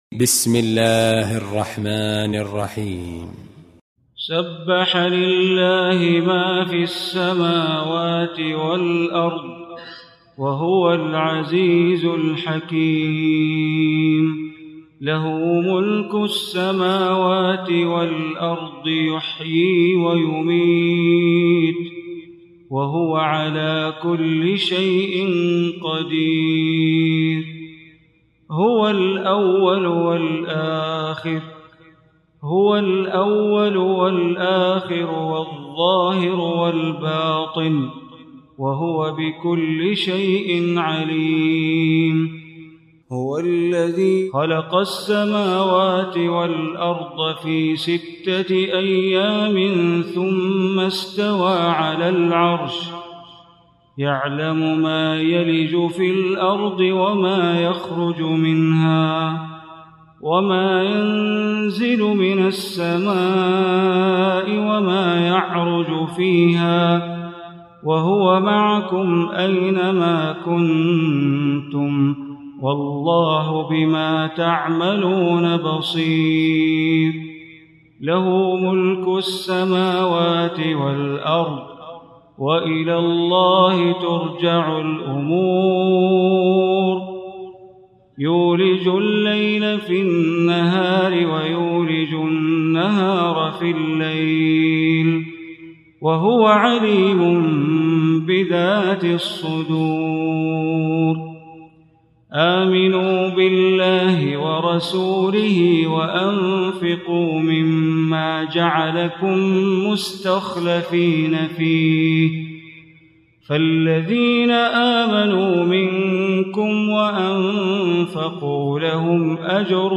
Surah Hadid Recitation by Sheikh Bandar Baleela
Surah Hadid, listen online mp3 tilawat / recitation in Arabic, recited by Imam e Kaaba Sheikh Bandar Baleela.